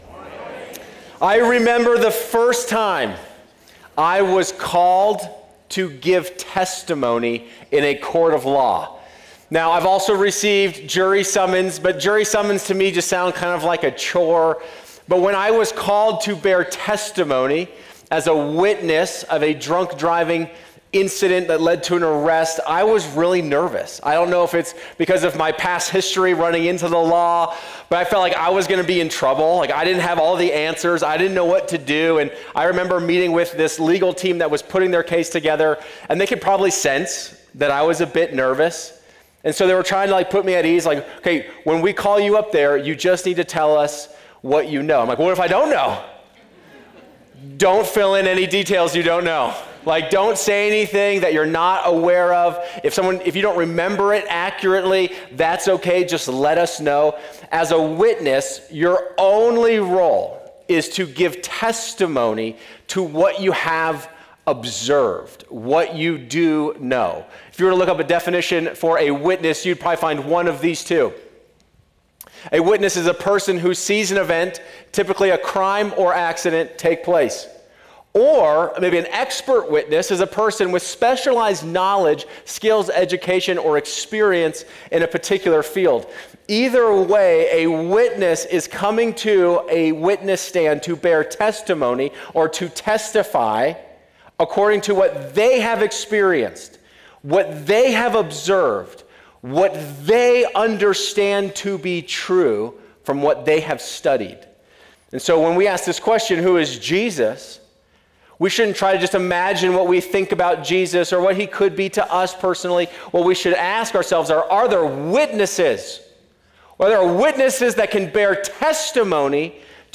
In this thought-provoking sermon, we delve into John chapter 6 to explore the profound questions of faith: Why do we follow Jesus?